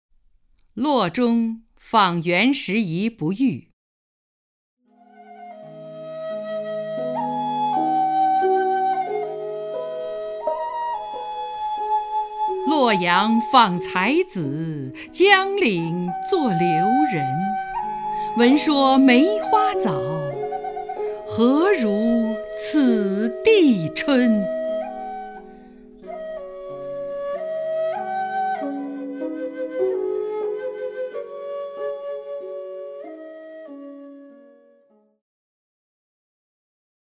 张筠英朗诵：《洛中访袁拾遗不遇》(（唐）孟浩然) （唐）孟浩然 名家朗诵欣赏张筠英 语文PLUS